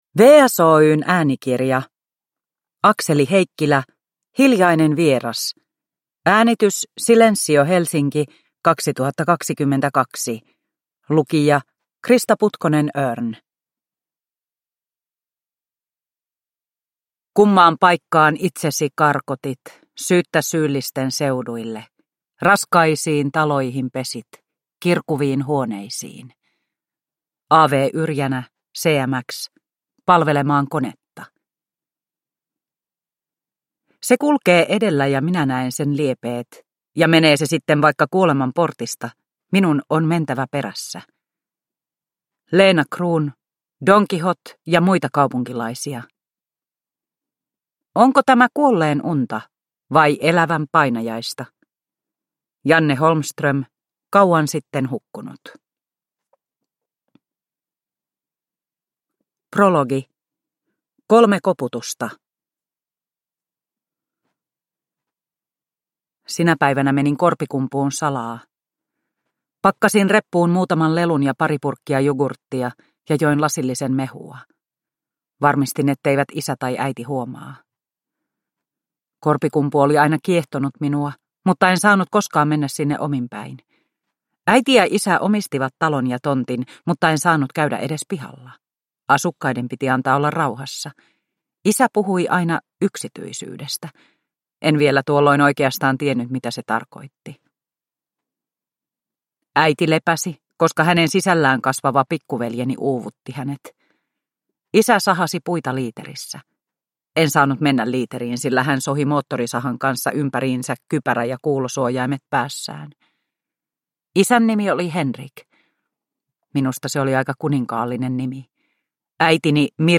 Hiljainen vieras – Ljudbok – Laddas ner